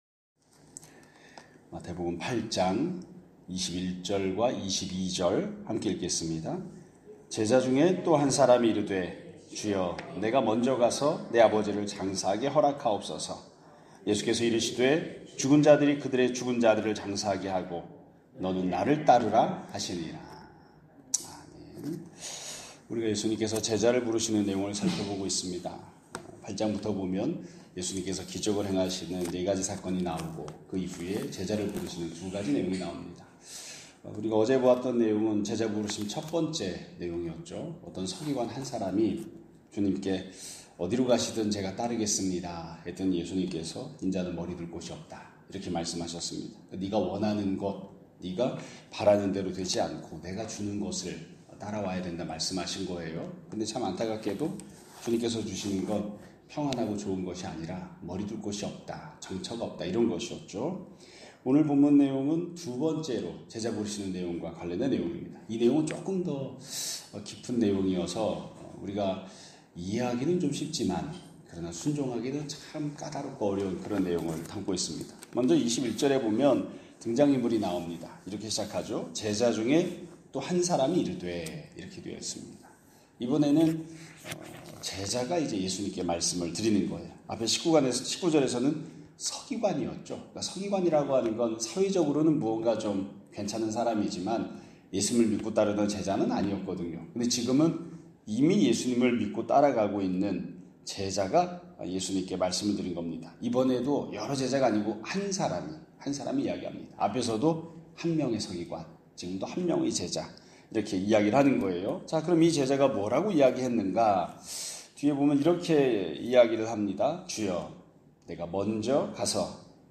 2025년 7월 15일(화요일) <아침예배> 설교입니다.